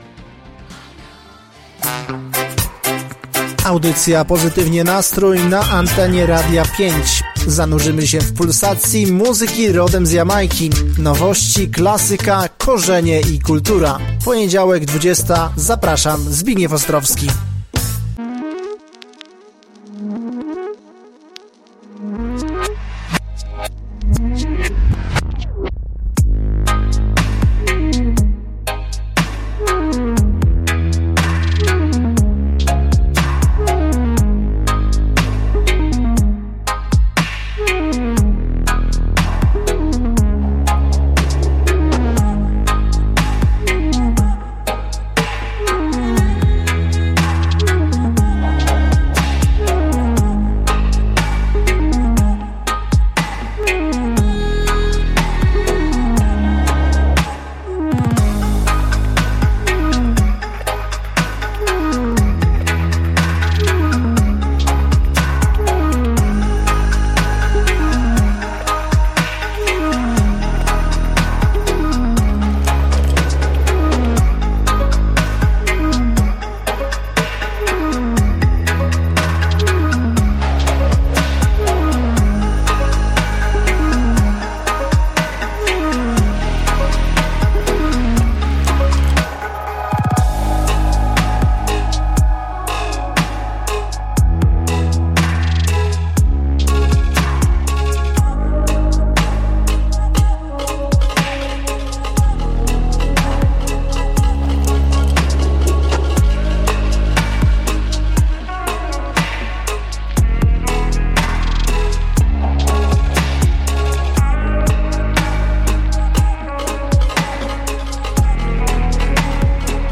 Wszystko doprawione basowym pulsem dubu.